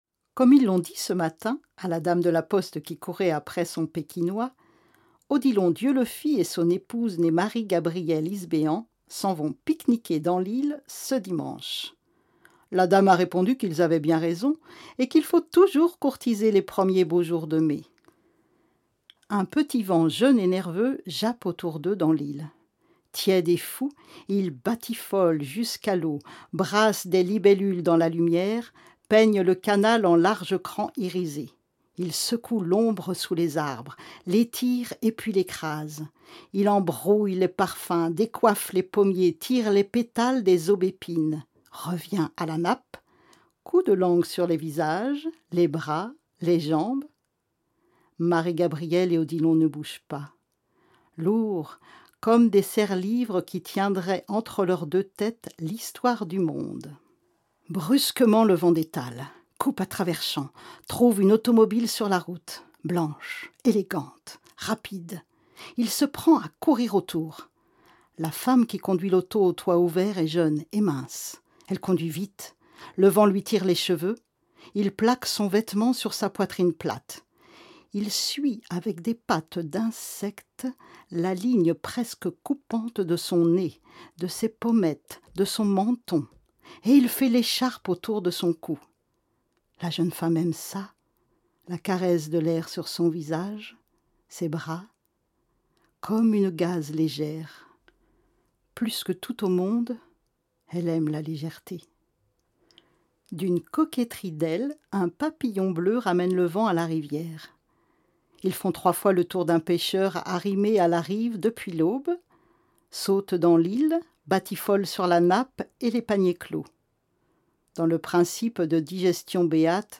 Mise en bouche d’extraits picorés dans les premières pages du roman :